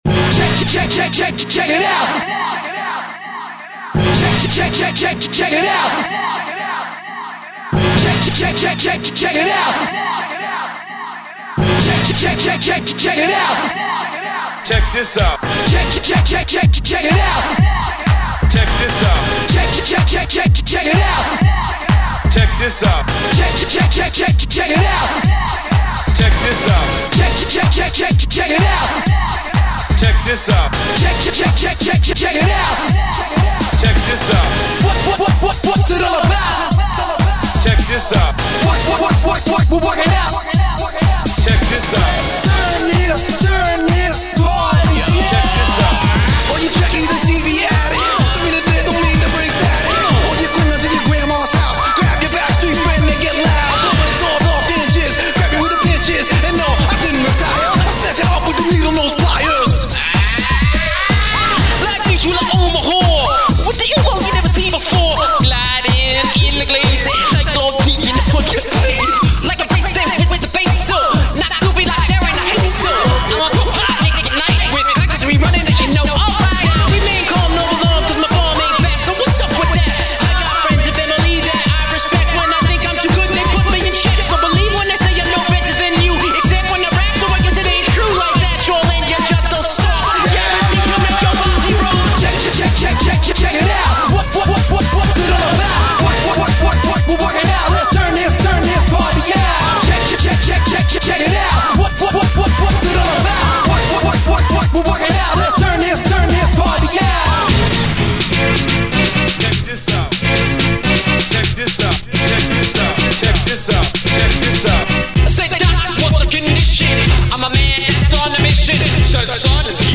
With bunches of vintage techno!